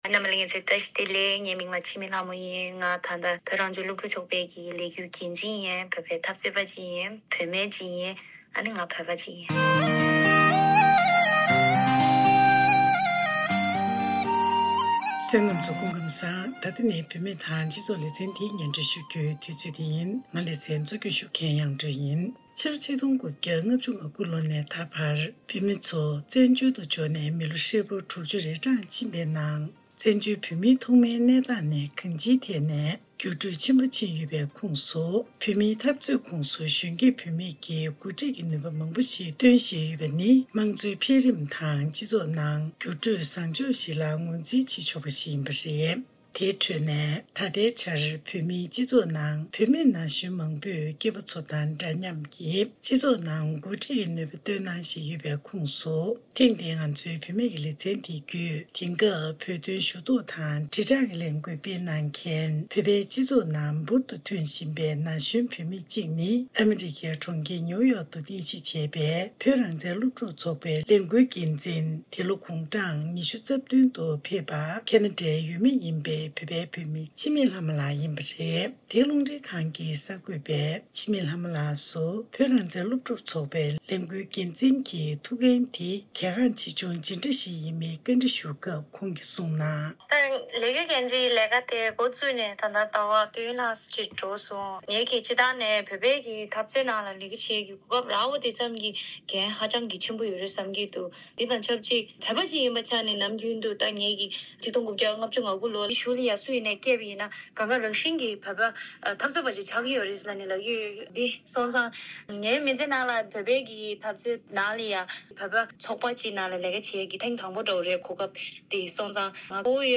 གནས་འདྲི་ཞུས་སྟེ་གནས་ཚུལ་ཕྱོགས་སྒྲིག་ཞུས་པ་ཞིག་གསན་རོགས་གནང།